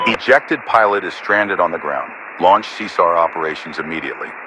Radio-commandFriendlyPilotOnGround.ogg